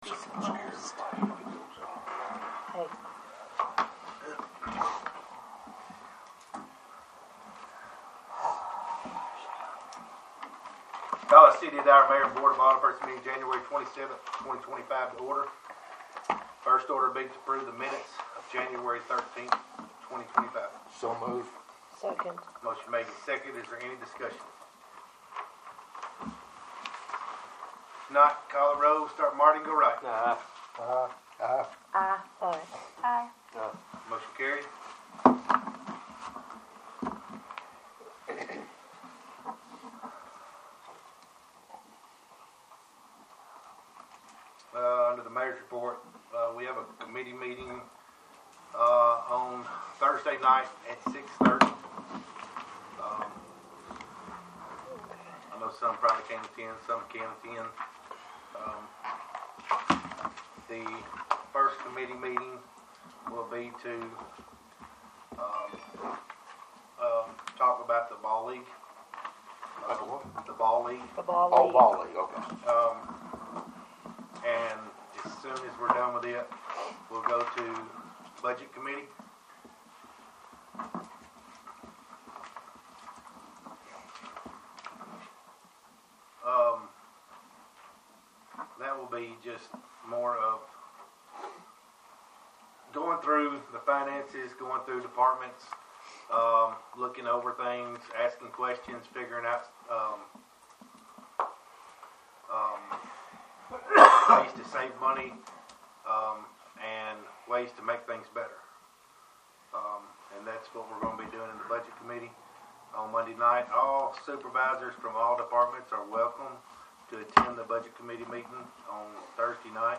1-27-25 Regular Meeting - City of Dyer, Tennessee
1-27-25-Regular-Meeting.mp3